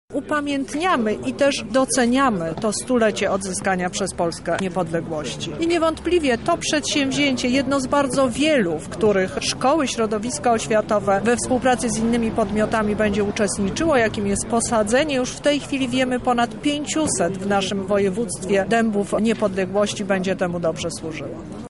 -mówi Teresa Misiuk, lubelska kurator oświaty.